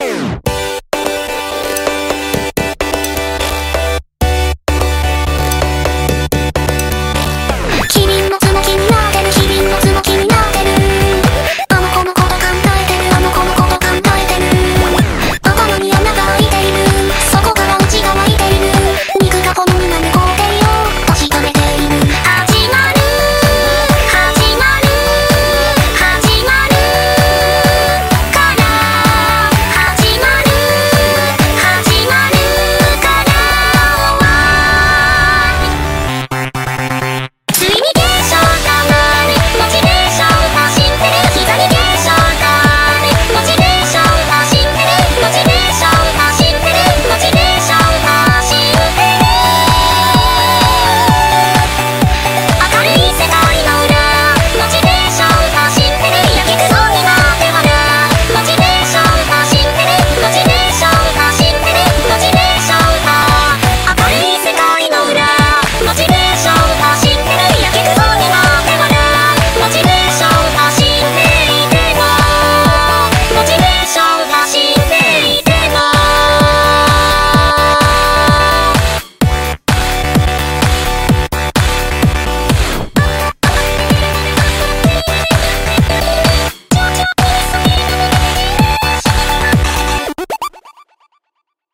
BPM128-128
Audio QualityPerfect (Low Quality)